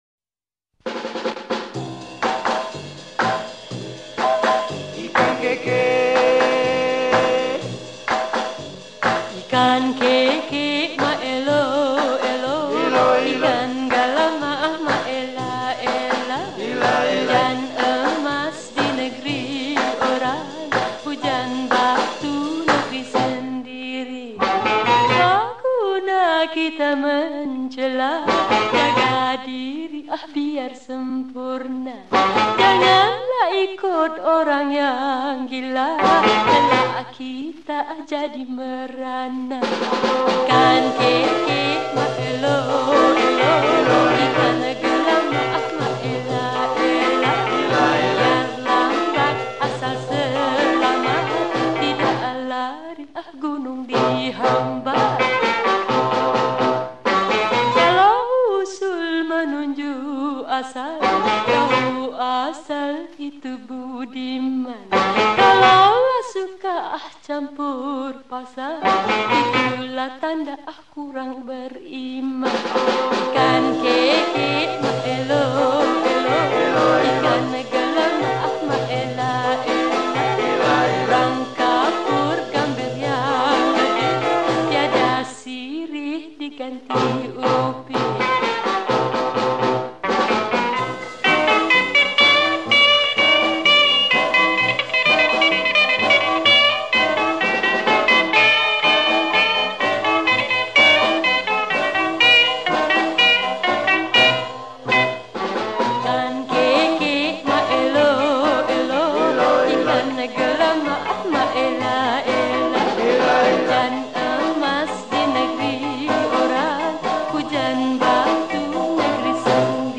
Lagu Kanak-kanak , Malay Songs